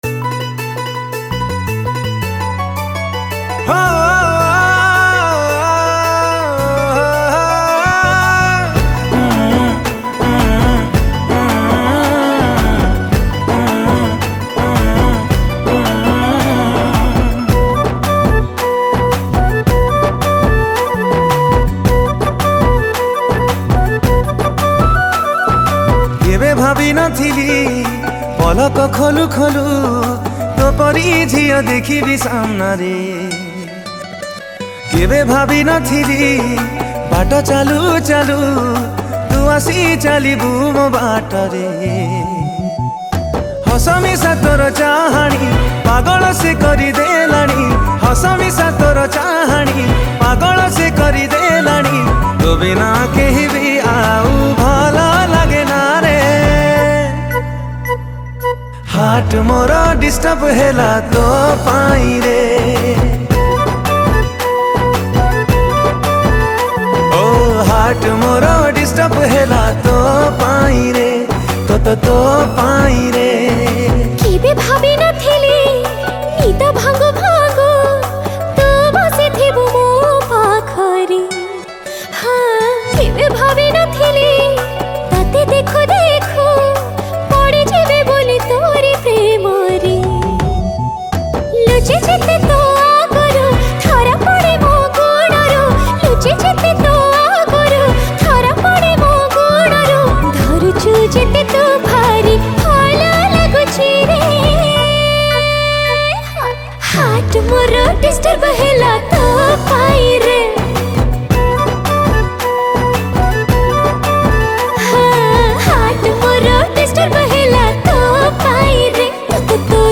Romantic Song Music